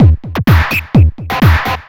DS 127-BPM A7.wav